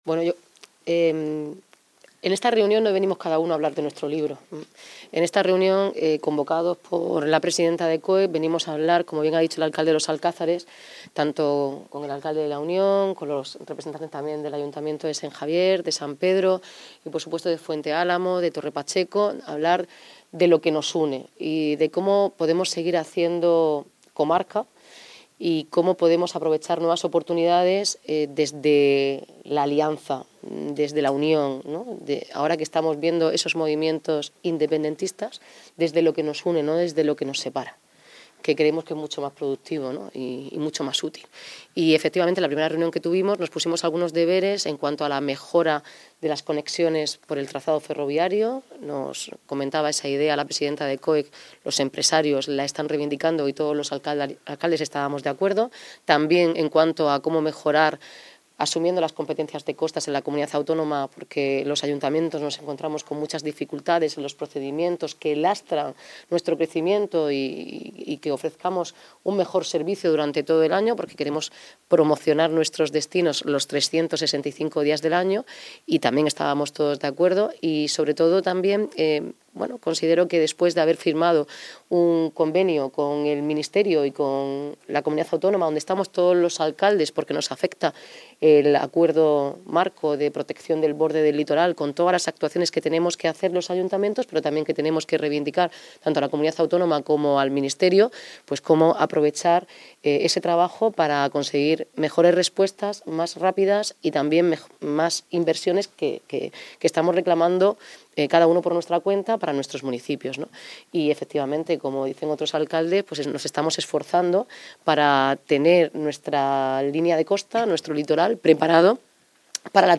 Enlace a Declaraciones de la alcaldesa